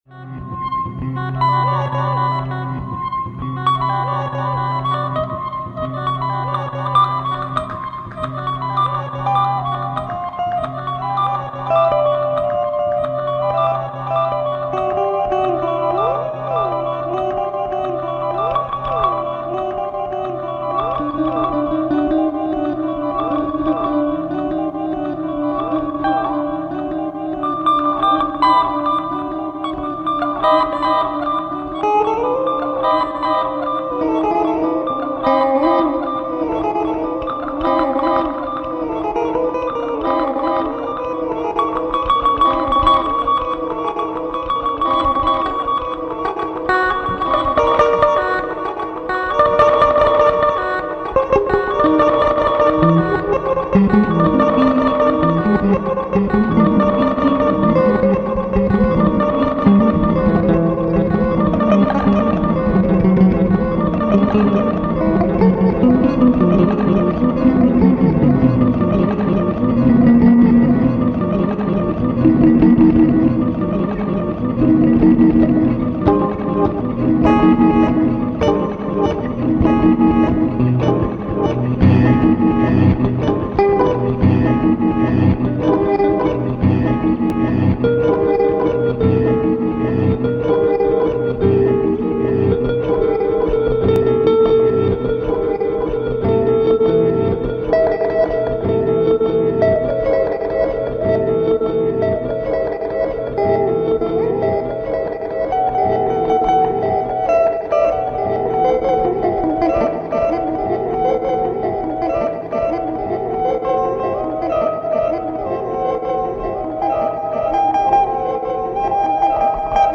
guitar-based examination of textures and moods